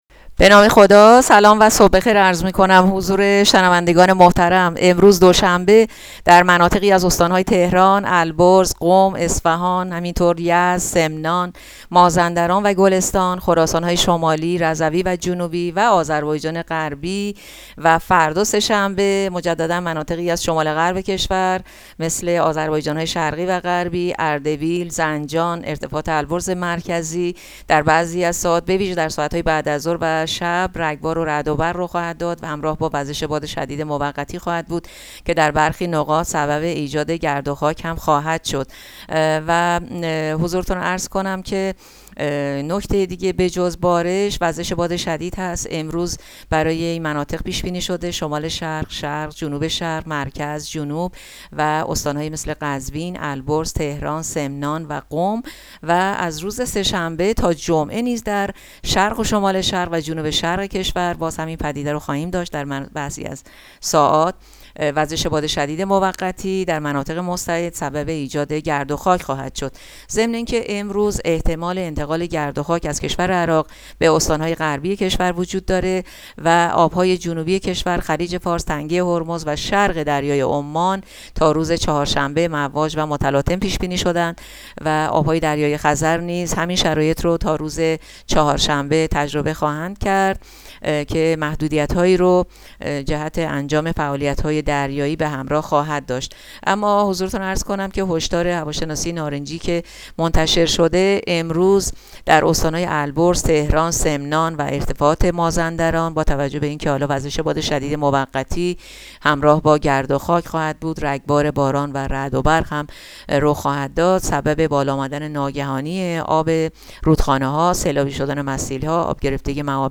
گزارش رادیو اینترنتی پایگاه‌ خبری از آخرین وضعیت آب‌وهوای ۵ خرداد؛